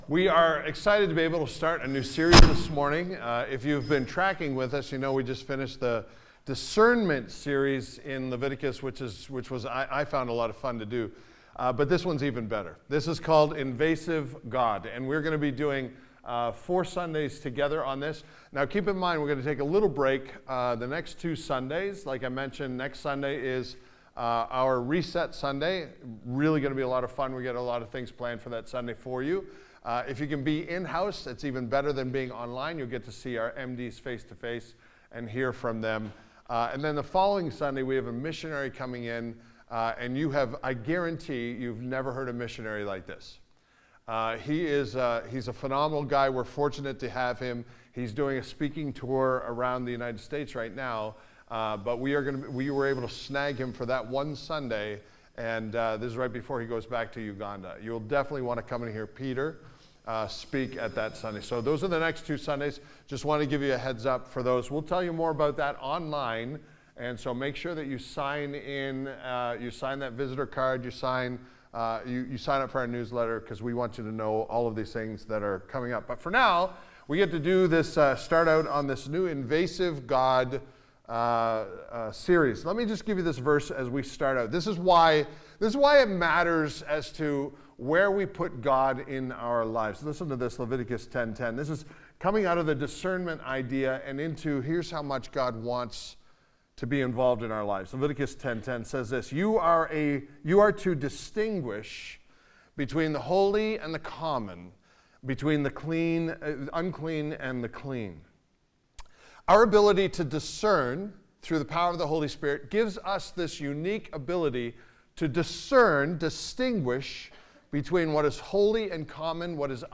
This sermon explors the complexity of how God invades the very mundane areas of our lives by even having requierments of what we eat and don't eat.